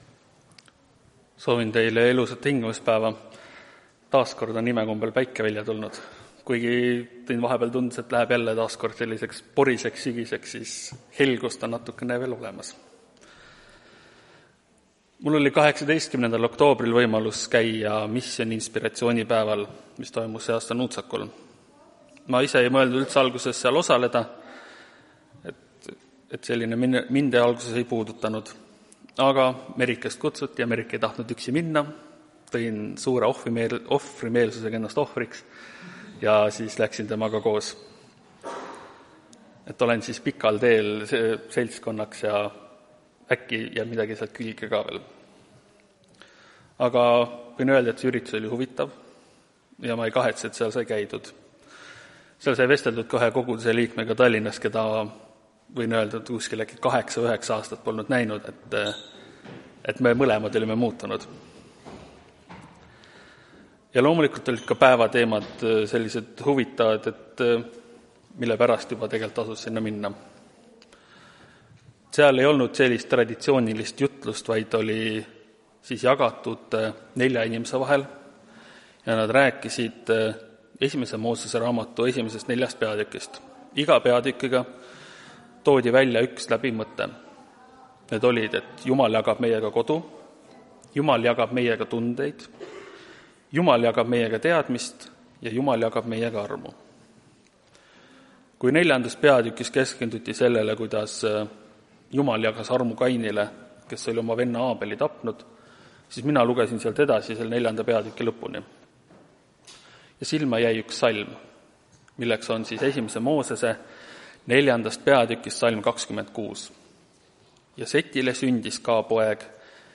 Tartu adventkoguduse 22.11.2025 teenistuse jutluse helisalvestis.